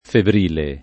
febrile [ febr & le ]